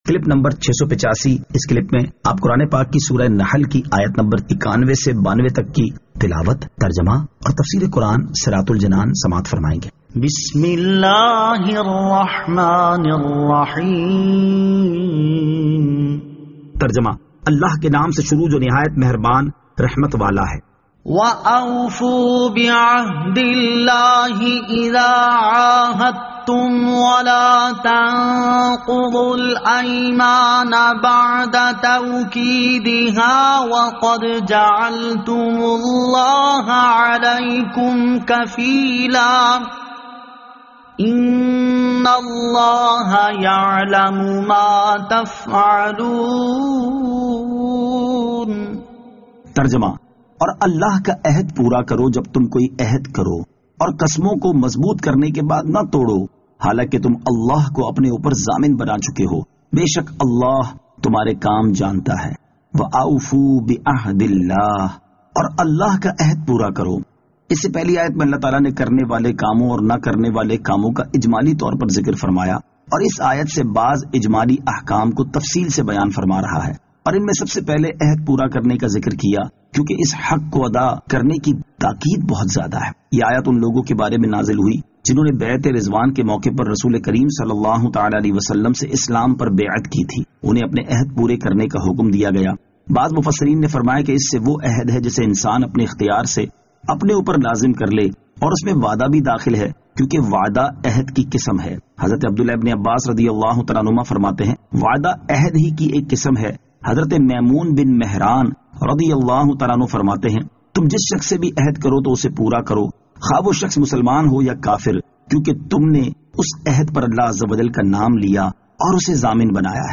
Surah An-Nahl Ayat 91 To 92 Tilawat , Tarjama , Tafseer